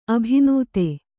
♪ abhinūte